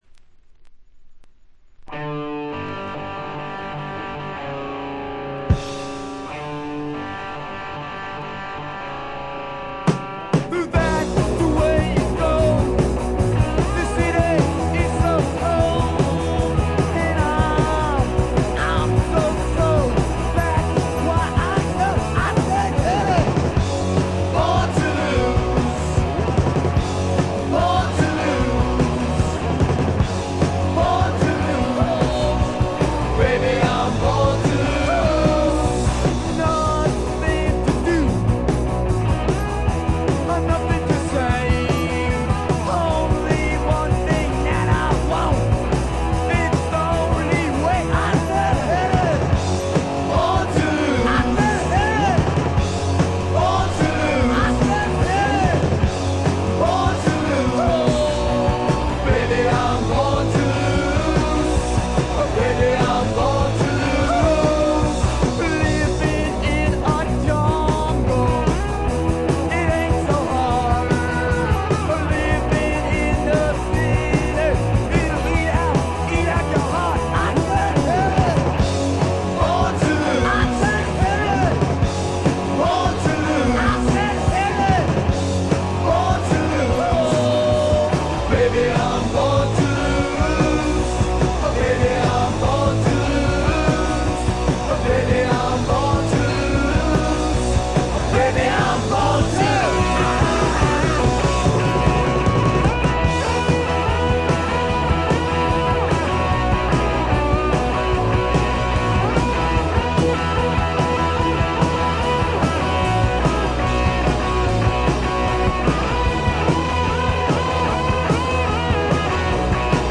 ほとんどノイズ感無し。
試聴曲は現品からの取り込み音源です。